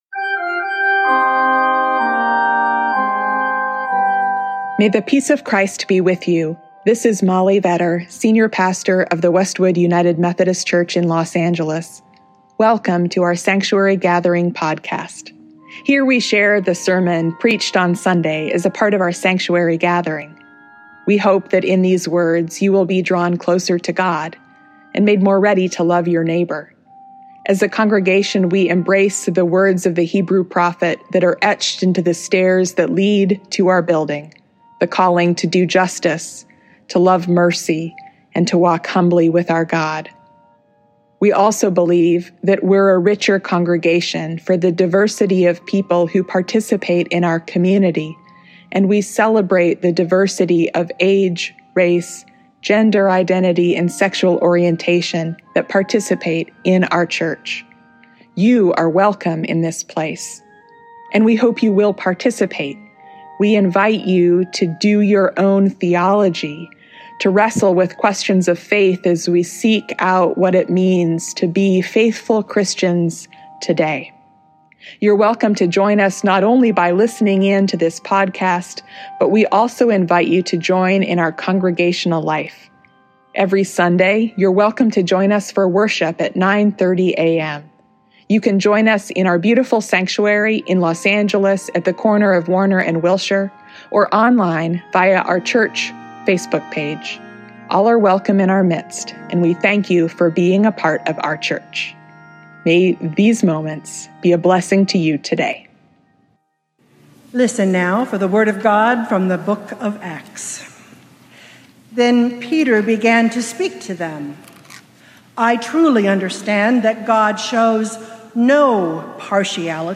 Join us to celebrate Easter with trumpet fanfare and a joyful procession in our beautiful sanctuary. We invite you to join us to sing resurrection hymns and to hear a word of hope – about a love that triumphs over death.